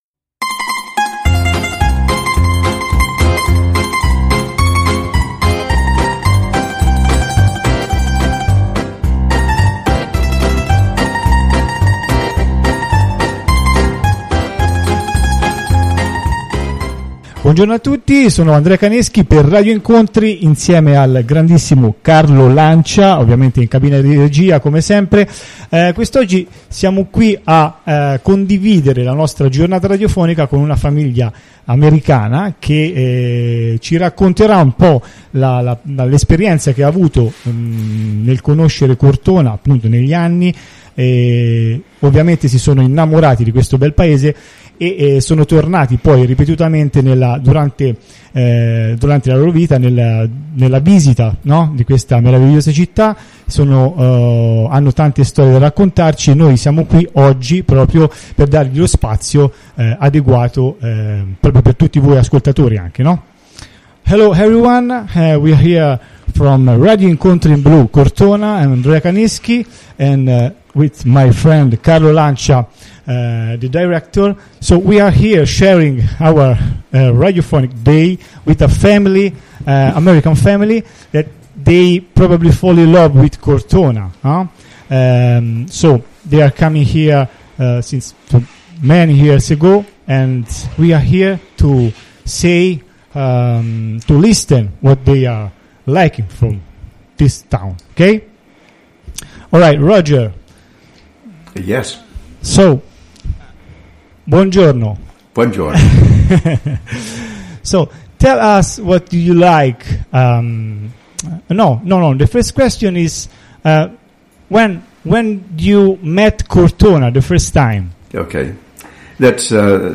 Uno speciale sugli studenti che furono della Università della Georgia. Intervista